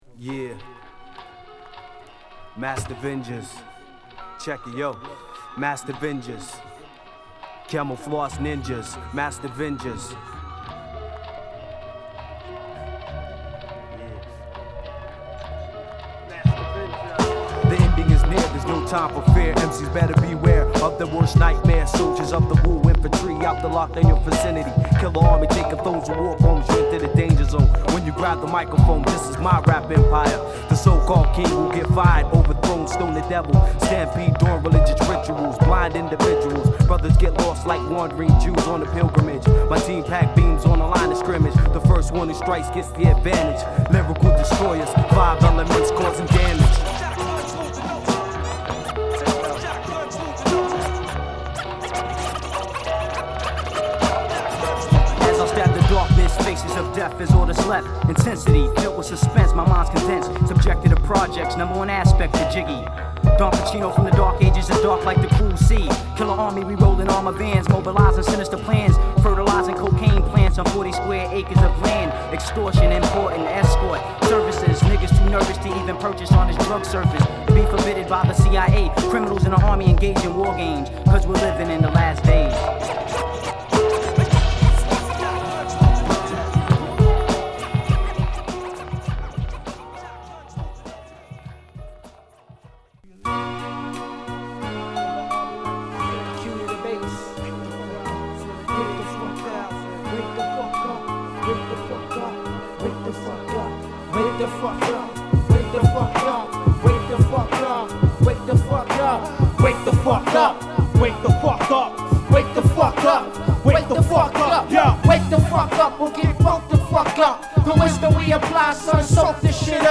ヒリヒリした緊張感に哀愁まで漂わせるドープな1曲を